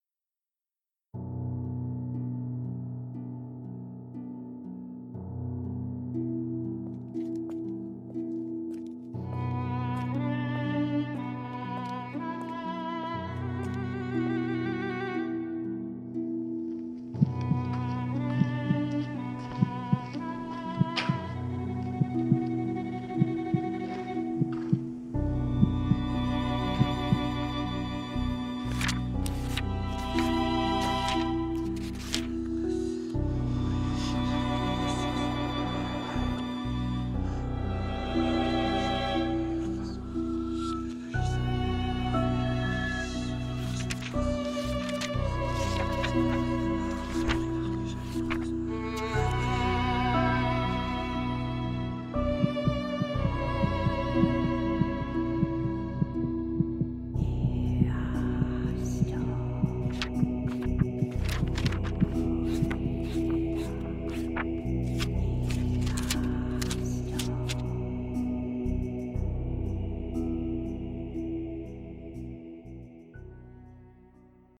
MIjrC7CM0jM_livre-journal-qui-tourne.mp3